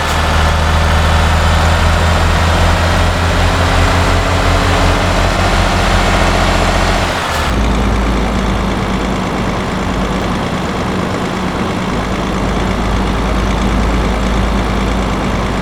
Index of /server/sound/vehicles/lwcars/truck_daf_xfeuro6
fourth_cruise.wav